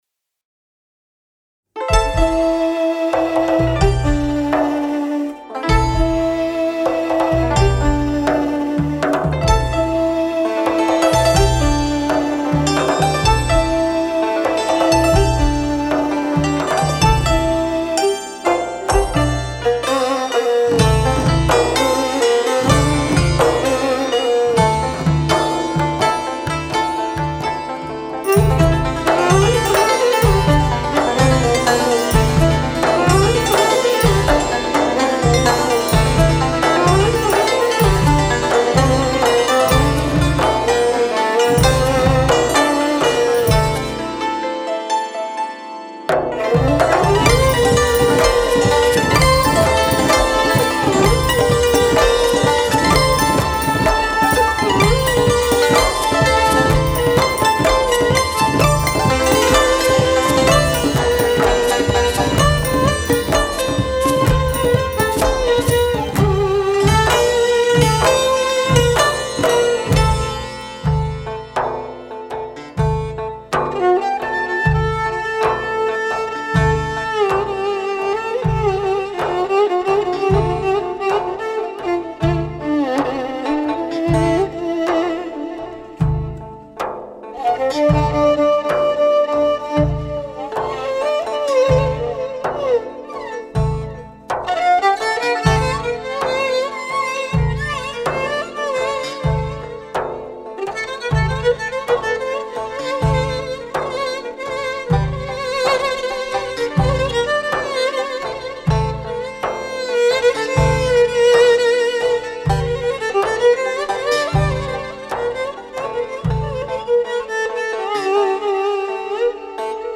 قطعه ای برای سنتور ویلن و سازهای کوبه ای